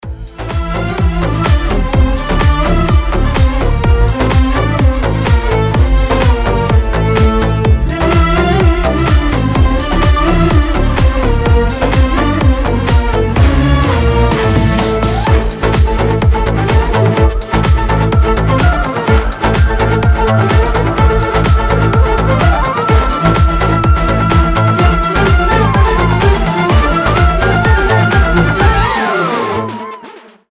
заводные
без слов
восточные
Хоть и похоже на индийские